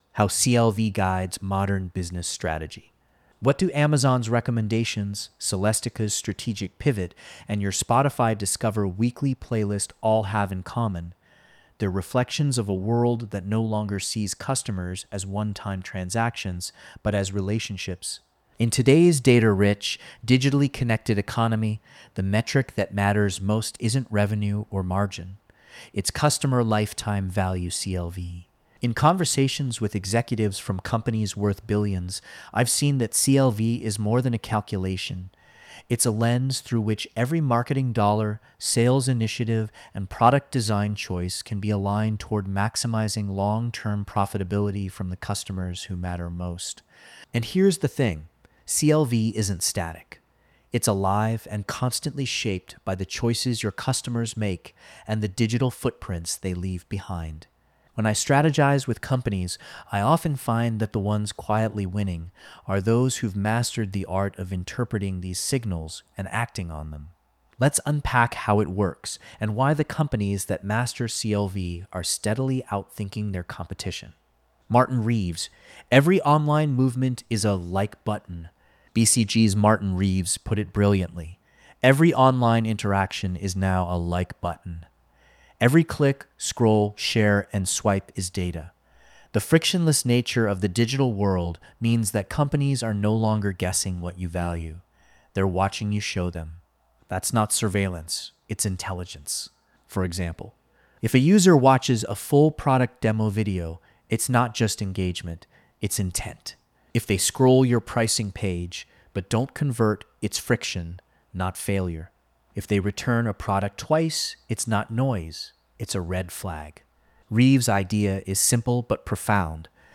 This audio was recorded by AI: